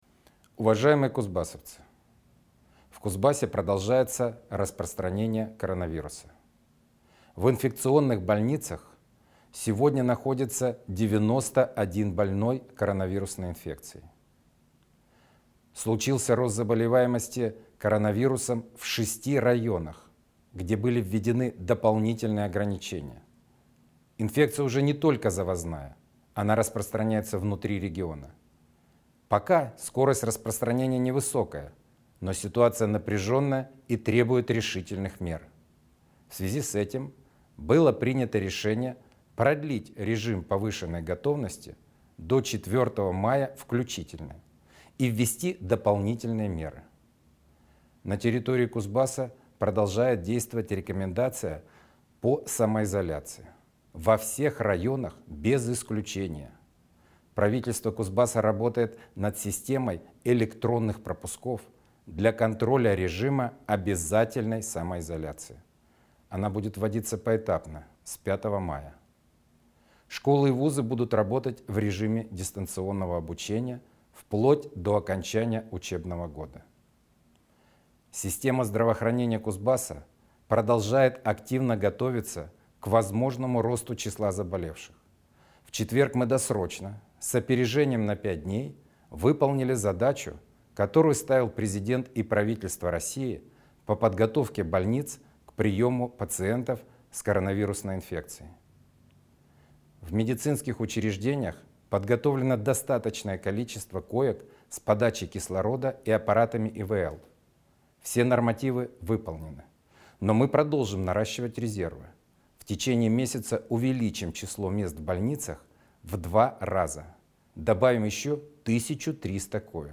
Обращение Сергея Цивилева 25 апреля.
obrashhenie_sergeja_civileva_25_aprelja.mp3